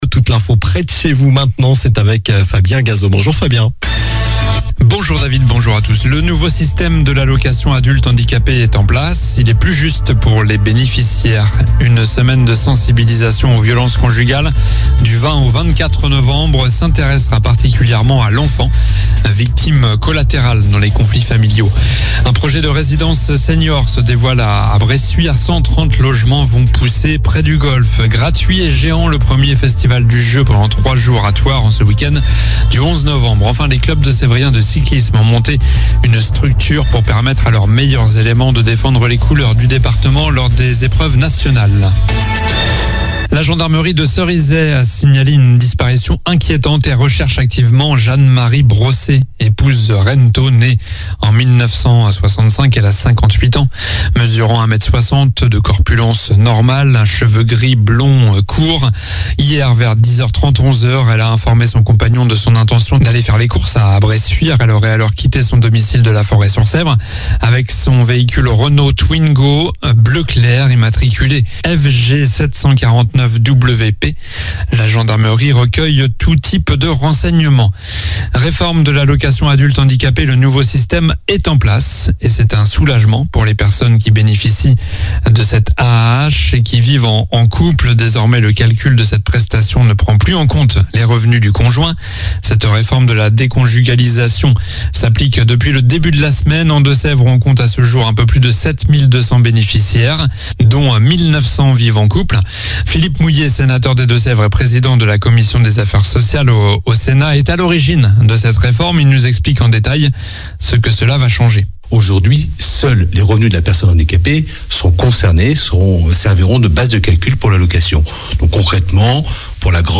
Journal du jeudi 09 novembre (soir)